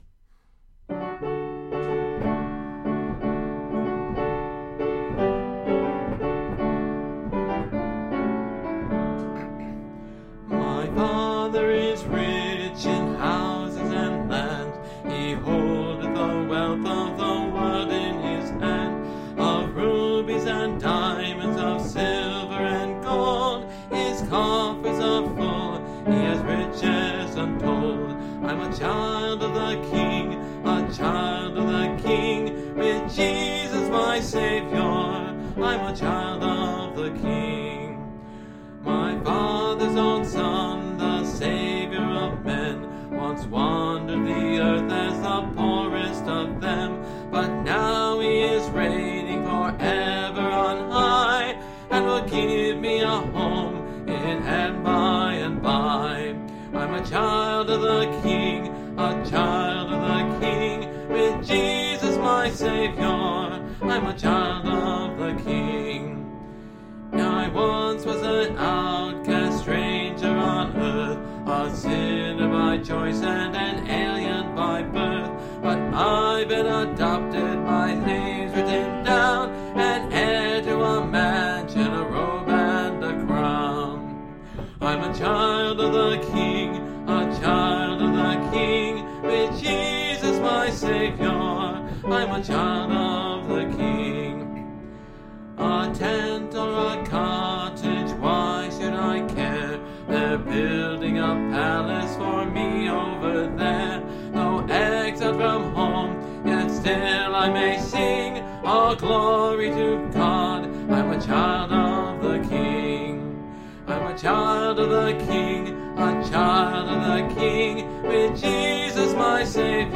Music, Uncategorized 1 Minute
(Part of a series singing through the hymnbook I grew up with: Great Hymns of the Faith)